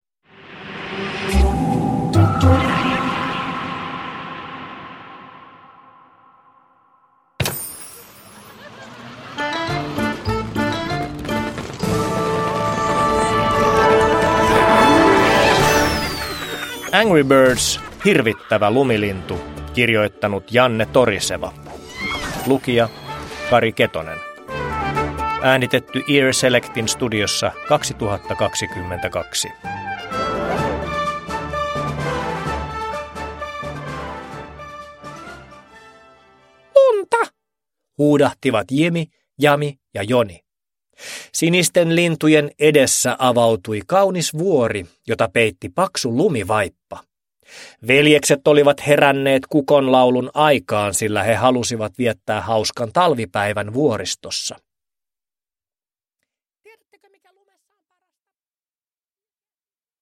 Angry Birds: Hirvittävä lumilintu – Ljudbok – Laddas ner
Uppläsare: Kari Ketonen